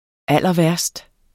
Udtale [ ˈalˀʌˈvæɐ̯sd ]